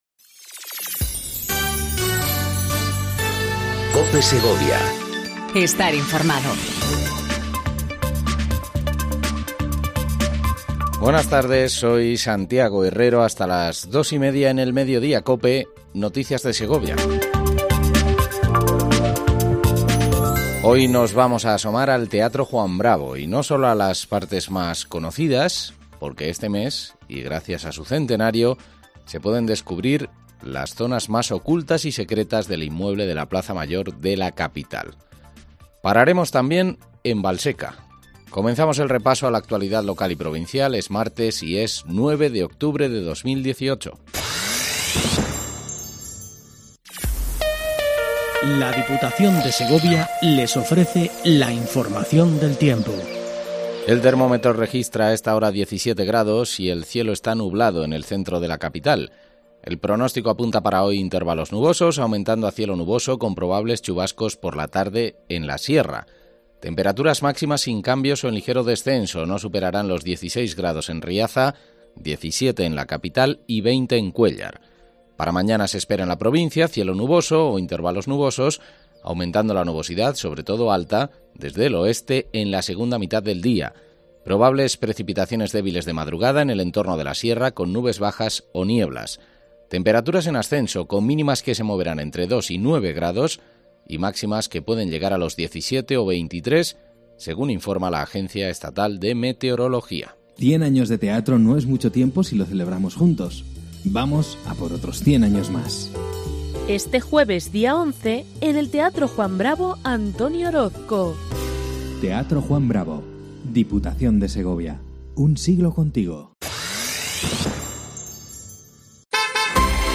INFORMATIVO DE MEDIODÍA EN COPE SEGOVIA 14:20 DEL 09/10/18